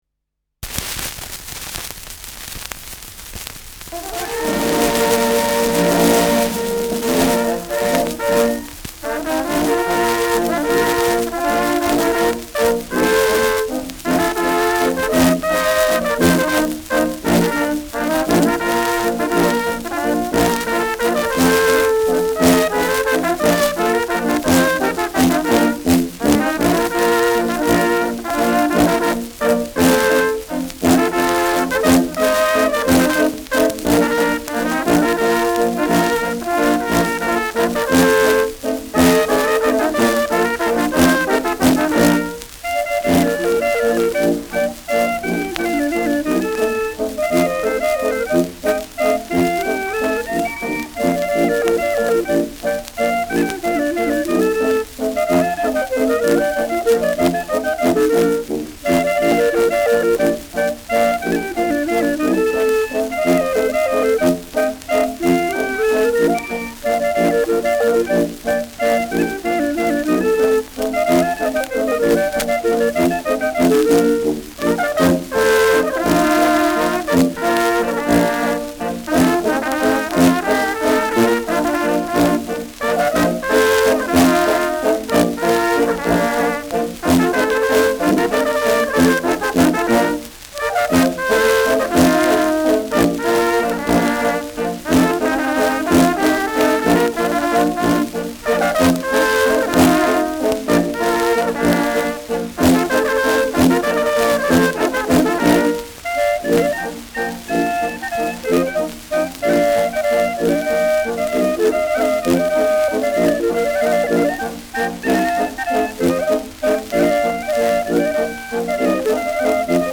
Schellackplatte
Tonrille: leichte Kratzer 9-3 Uhr : Schlieren
präsentes Rauschen
Dachauer Bauernkapelle (Interpretation)